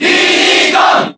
Diddy_Kong_Cheer_Japanese_SSBB.ogg